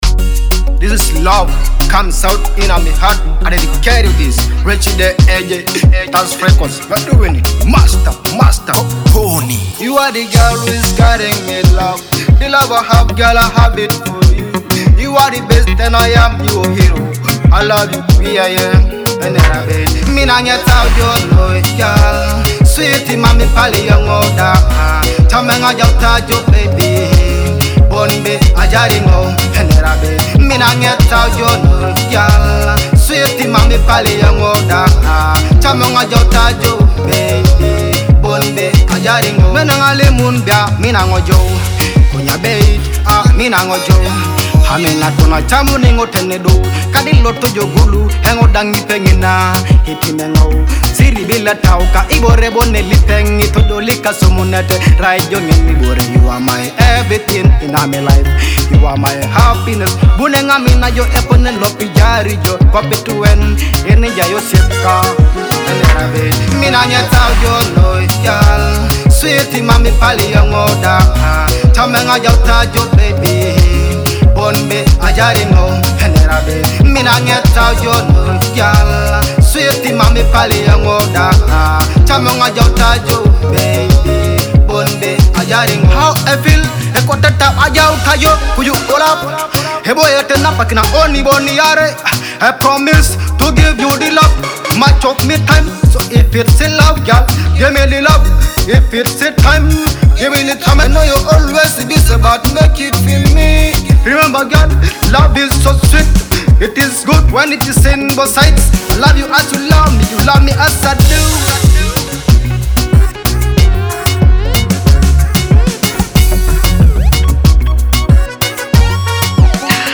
a captivating Teso love song celebrating romance
heartfelt Teso love song